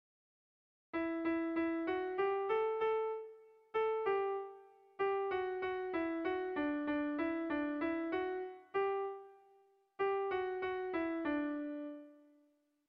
Tragikoa
Ataun < Goierri < Gipuzkoa < Euskal Herria
Lauko txikia (hg) / Bi puntuko txikia (ip)
A1A2